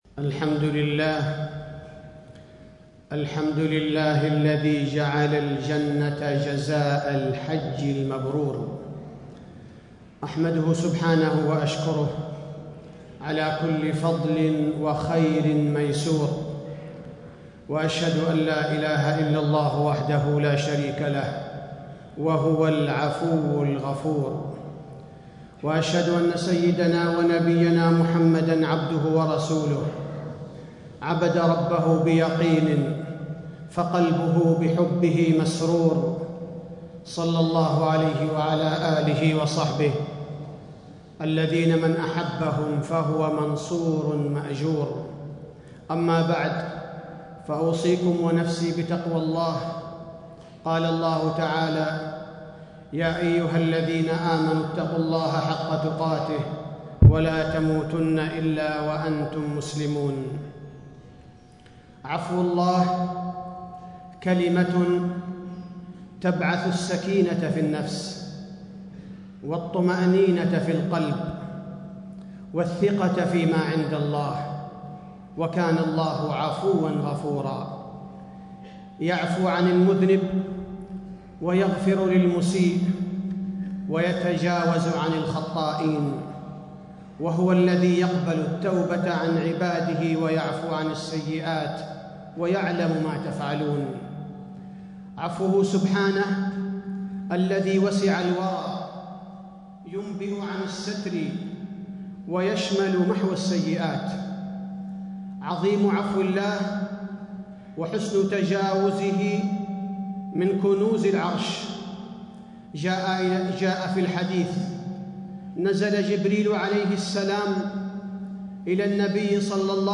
تاريخ النشر ٩ ذو الحجة ١٤٣٥ هـ المكان: المسجد النبوي الشيخ: فضيلة الشيخ عبدالباري الثبيتي فضيلة الشيخ عبدالباري الثبيتي مظاهر عفو الله تعالى على عباده